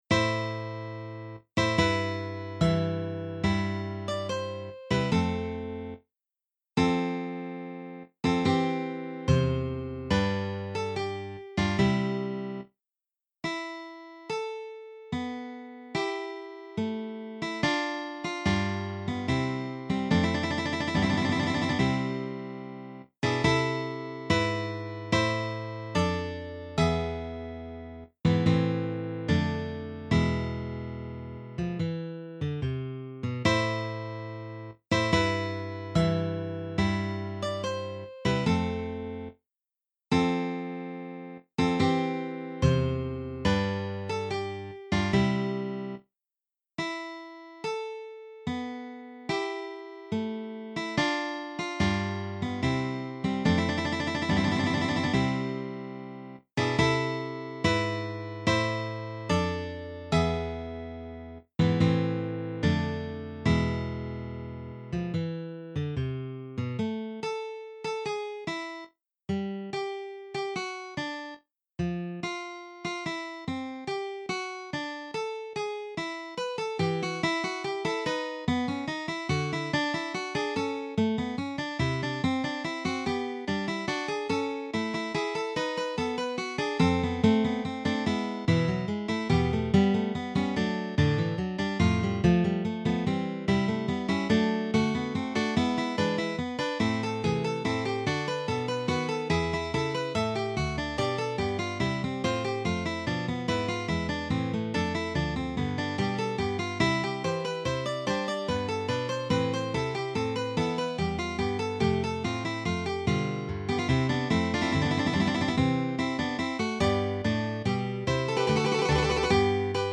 arranged for Guitar Quartet
Baroque/Classical